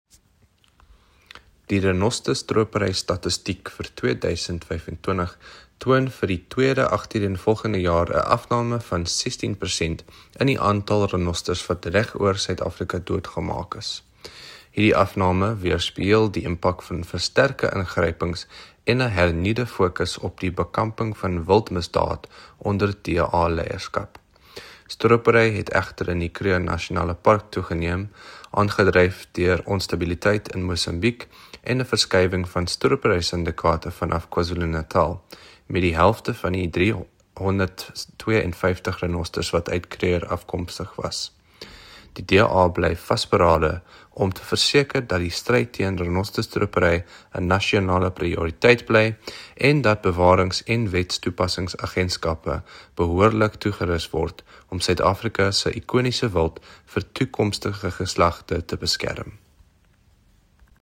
Afrikaans soundbite by Andrew de Blocq MP.